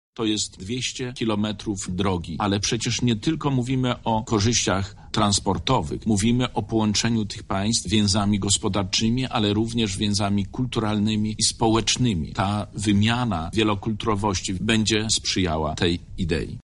Skupimy się wokół osi Via Karpatia – mówi Zbigniew Wojciechowski, wicemarszałek Województwa Lubelskiego.